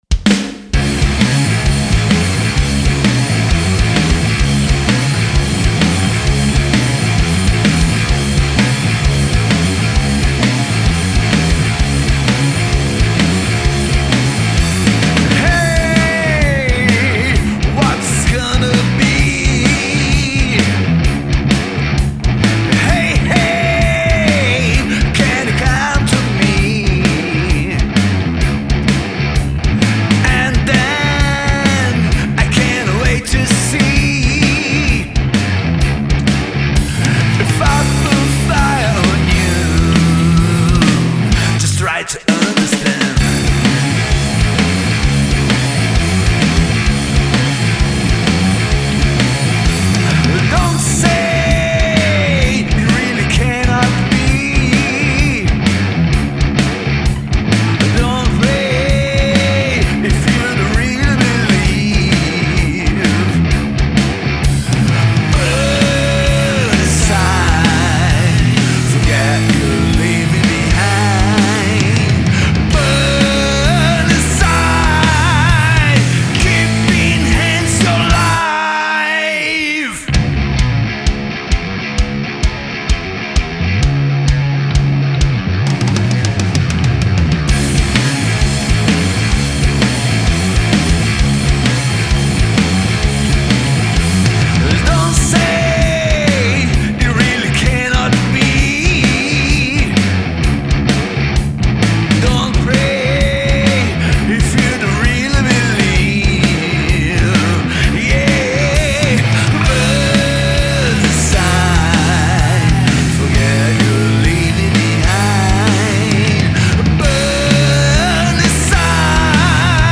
voz e baixo
guitarra